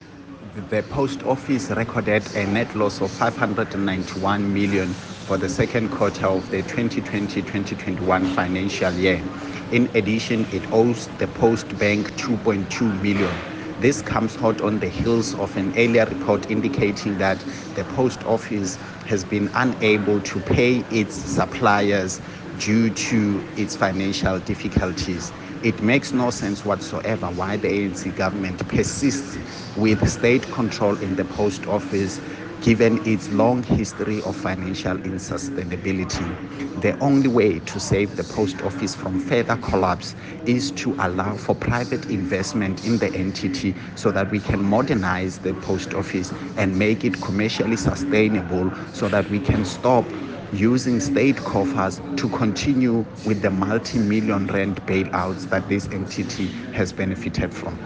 soundbite by Solly Malatsi MP.